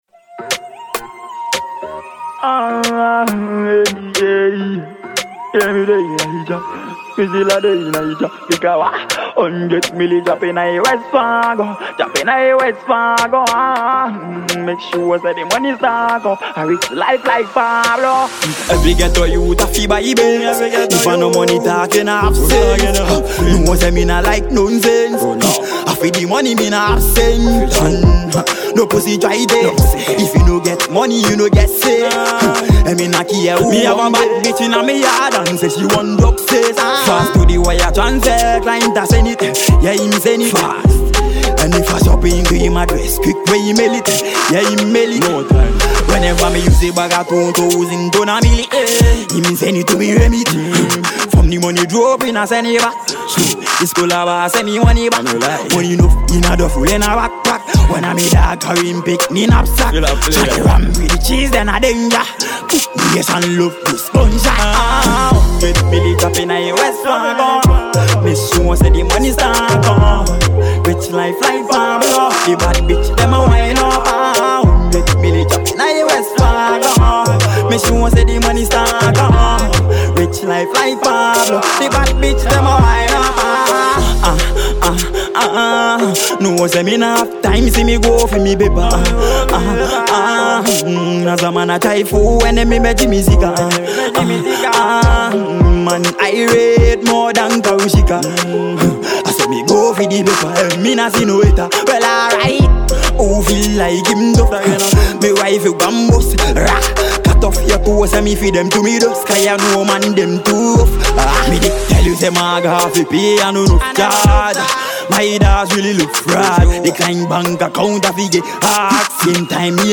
a Ghanaian dancehall acr